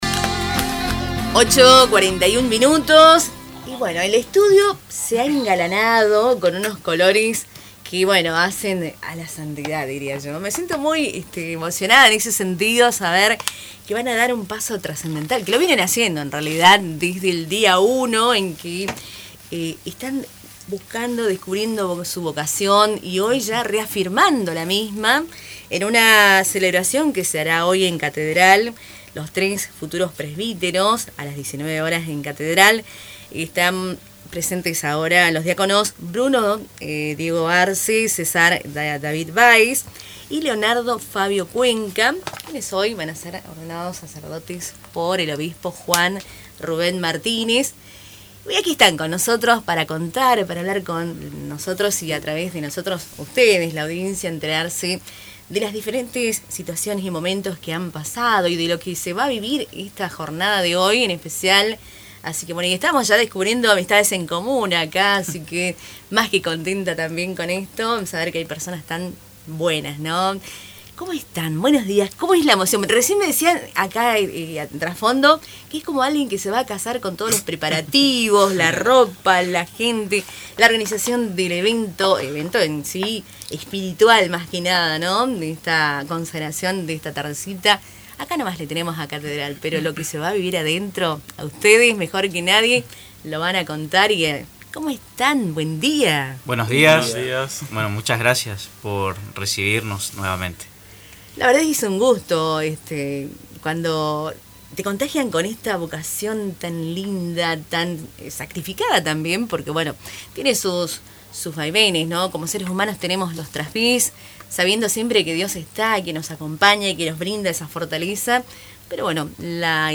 Hoy, el estudio de Radio TupaMbaé se impregnó de bendiciones al contar con el honor de entrevistar a los futuros presbíteros, quienes compartieron sus emociones y expectativas en una exclusiva conversación antes de la solemne ceremonia. Los invitados reflexionaron sobre sus sentimientos y proyectaron sus expectativas no solo para el día de hoy, sino también para su prometedor futuro.